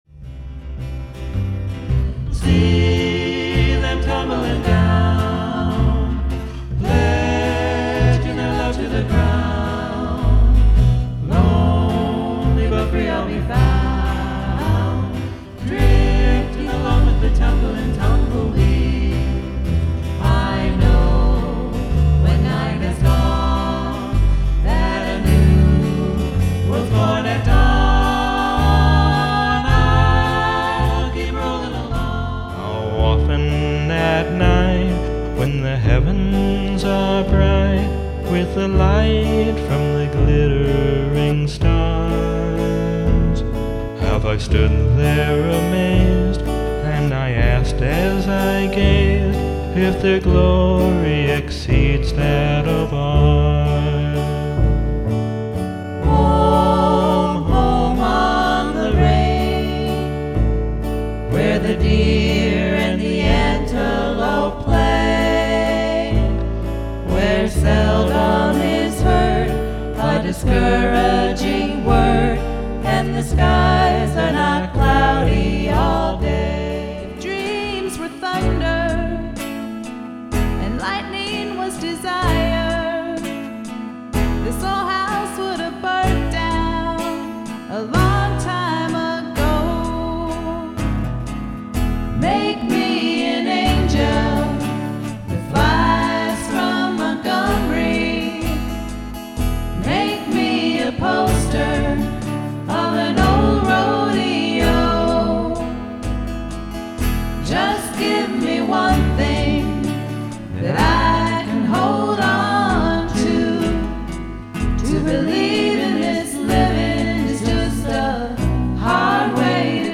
Early Rock, Classic Country, and Timeless Standards